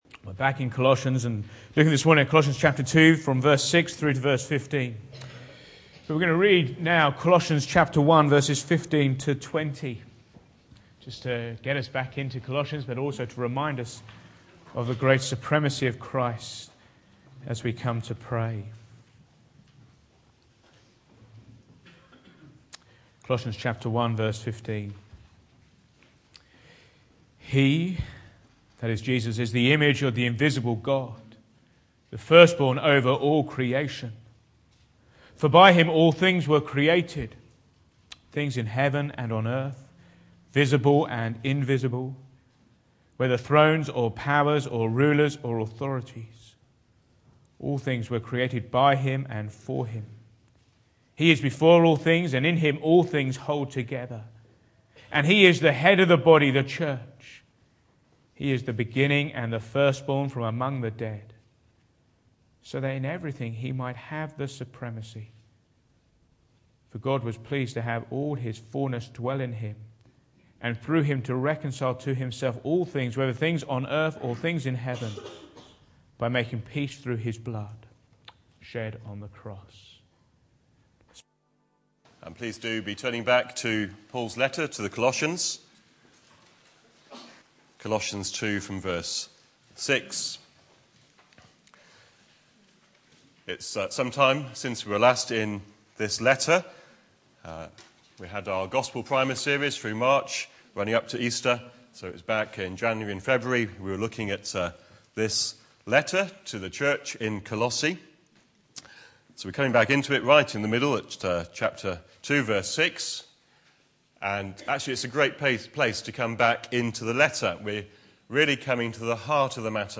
Back to Sermons Antidote to Error